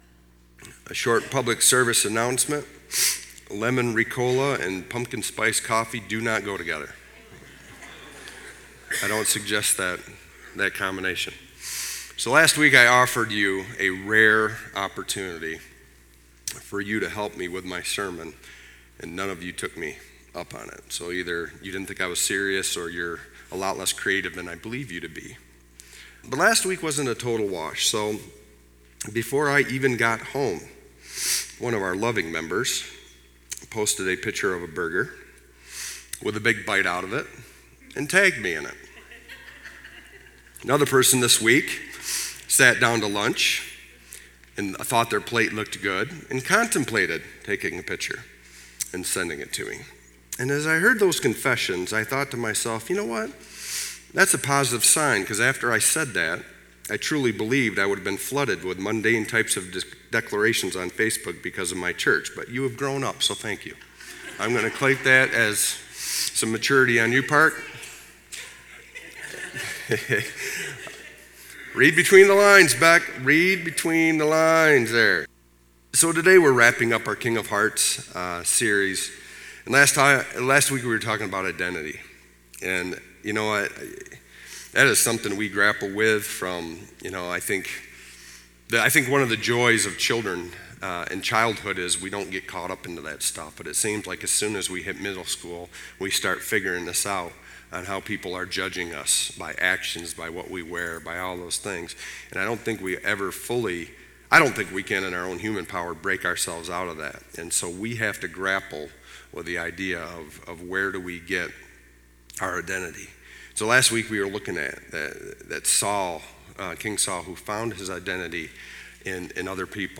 Sermons Sort By Date - Newest First Date - Oldest First Series Title Speaker What is REAL?